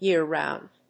アクセントyéar‐róund
音節year-round発音記号・読み方jɪ́əràʊnd|jə́ː-